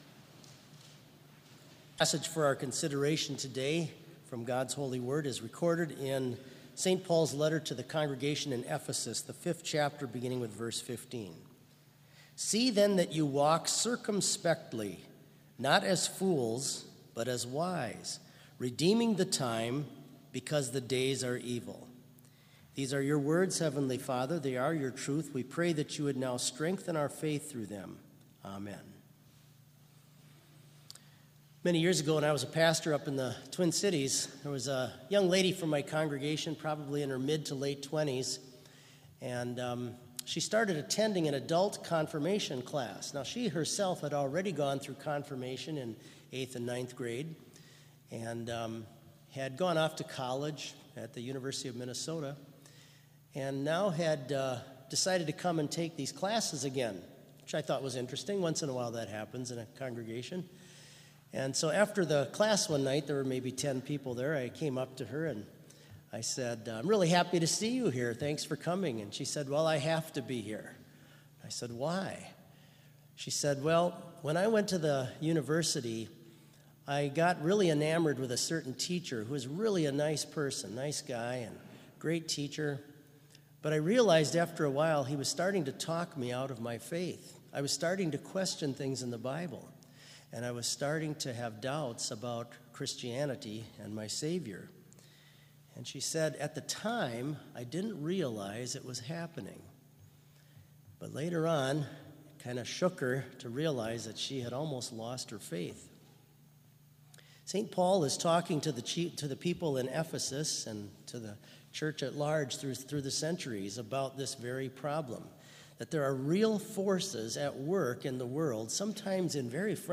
Complete Service
This Chapel Service was held in Trinity Chapel at Bethany Lutheran College on Monday, October 14, 2019, at 10 a.m. Page and hymn numbers are from the Evangelical Lutheran Hymnary.